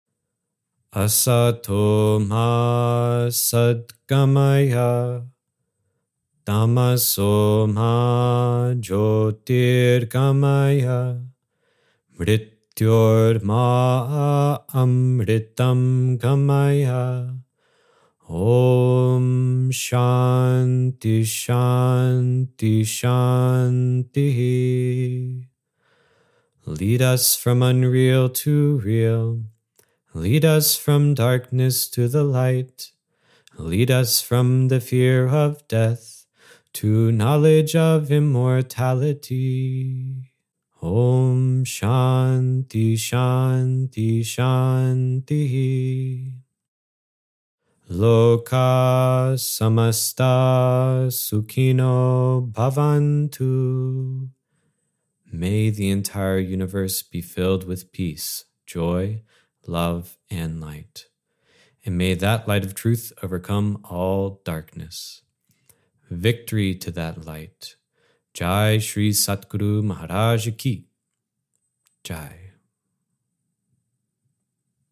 Peace Chants
Peace-Chants-with-added-EQ_1.mp3